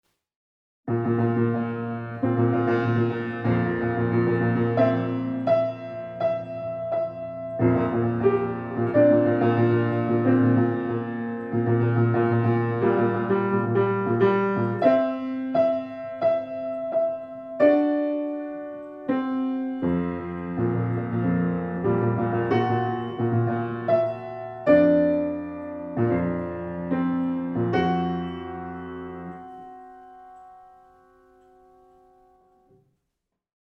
Musik zum Mantra 8 H — sehnend-sehnsüchtig — komponiert von Herbert Lippmann